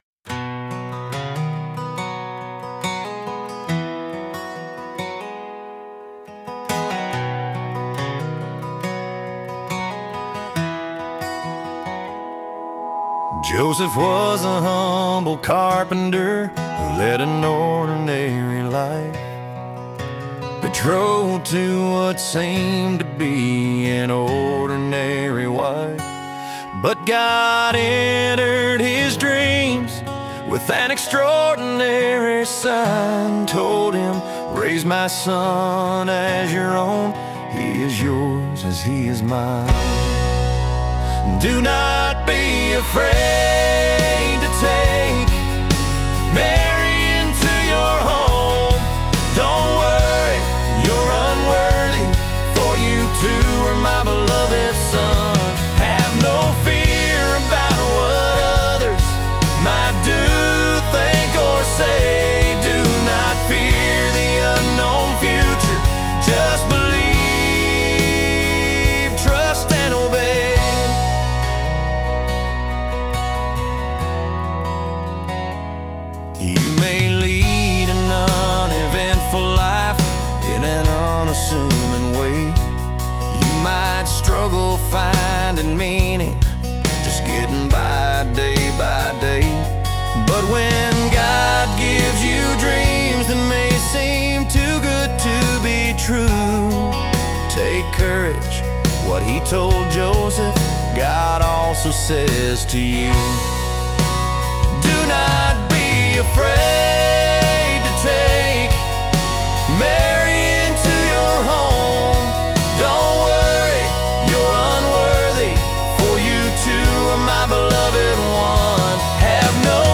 Country Rock